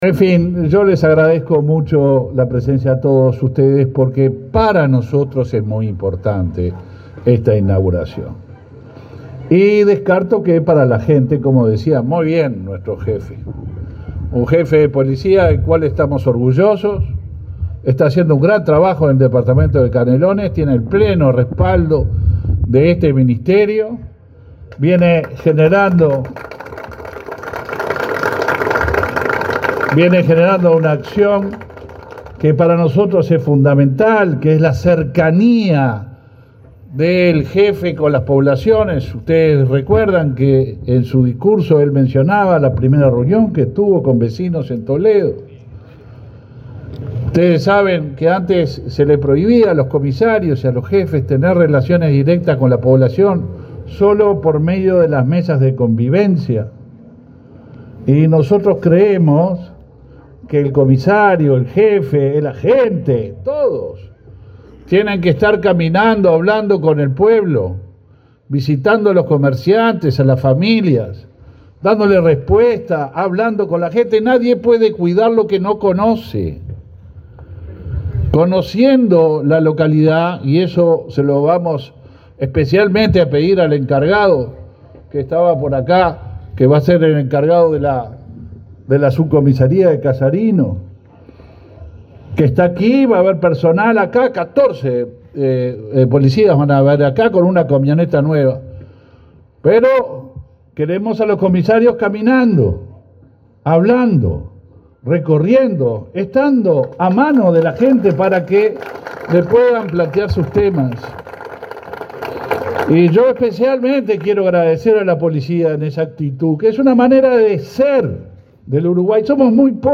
Palabras del ministro del Interior, Luis Alberto Heber
Palabras del ministro del Interior, Luis Alberto Heber 28/04/2023 Compartir Facebook X Copiar enlace WhatsApp LinkedIn Este viernes 28, el ministro del Interior, Luis Alberto Heber, participó en la inauguración de una subcomisaría en la localidad de Casarino, en el departamento de Canelones.